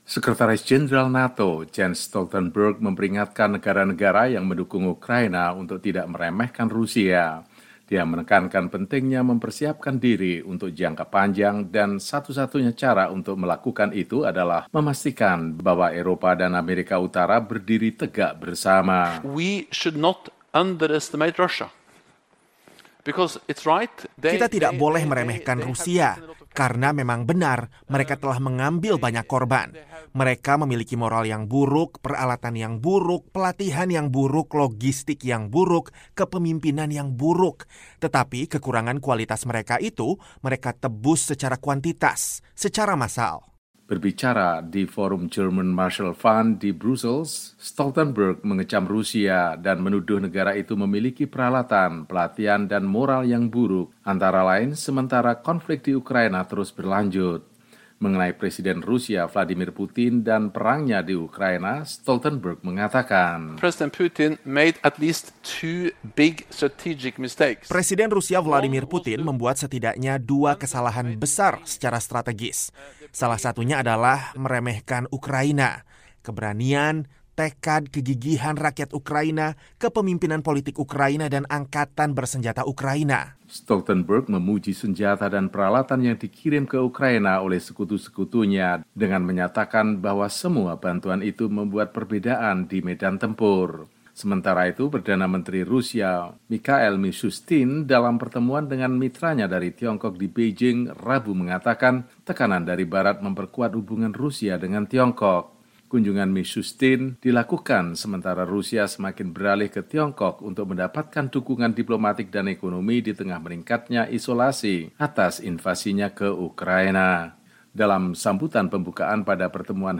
Berbicara di forum German Marshall Fund di Brussel, Stoltenberg mengecam Rusia, dan menuduh negara itu memiliki peralatan, pelatihan, dan moral yang “buruk,” antara lain sementara konflik di Ukraina terus berlanjut.